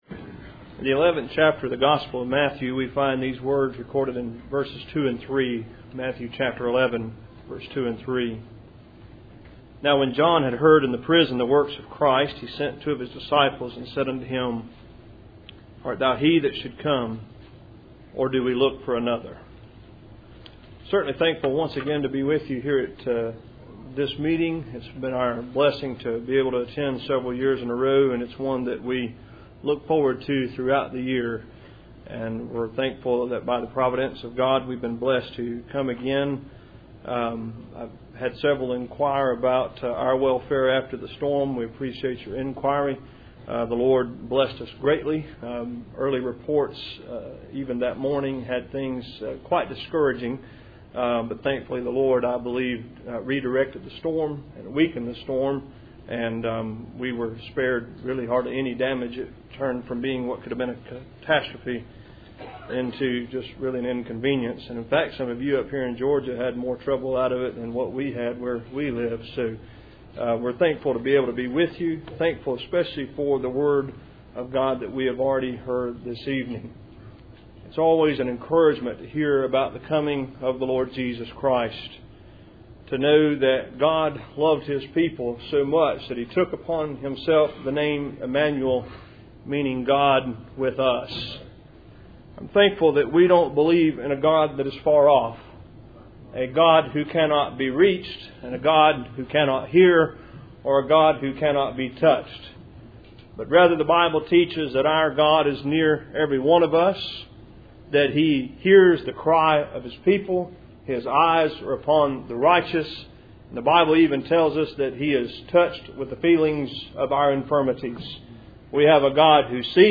Service Type: Ebenezer Fellowship Meeting